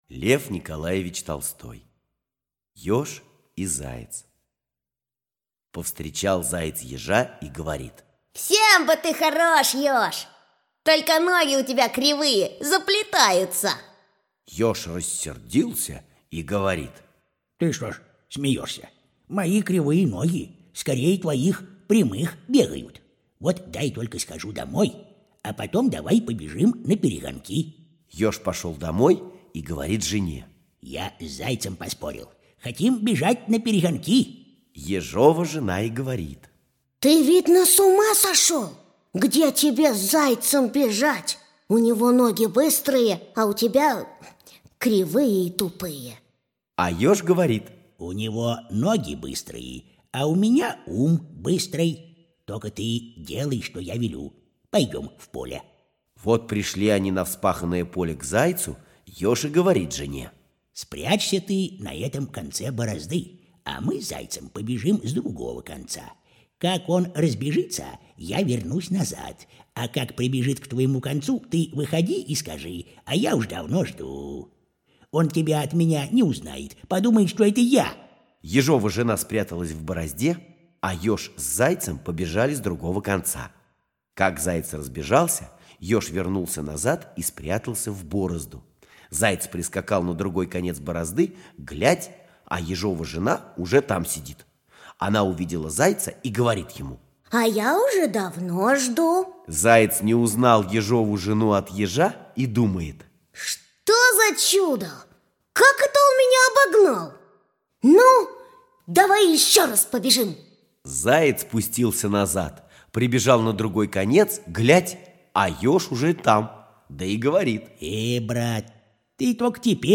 Ёж и заяц – Толстой Л.Н. (аудиоверсия)